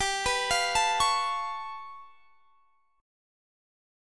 Listen to G9b5 strummed